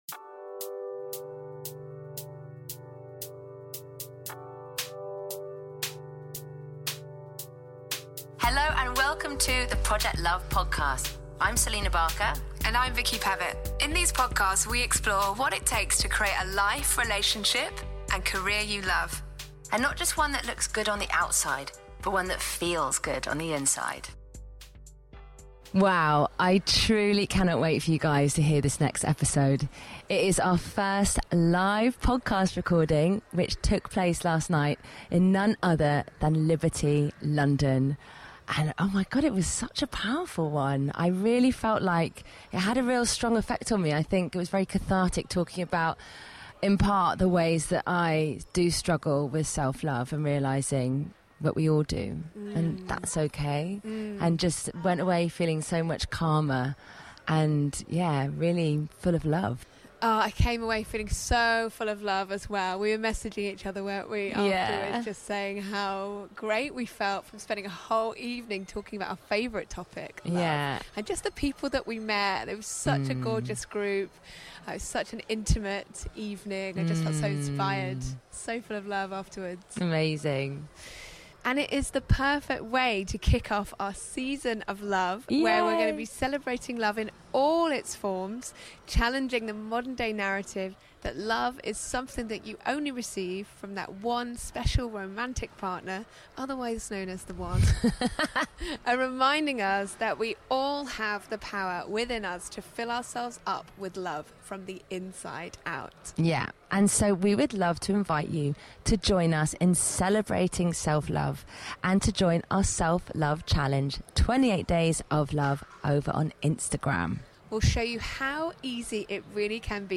Ep 124: LOVE: it’s not just for Valentine’s (from the archives!) - a live recording at Liberty London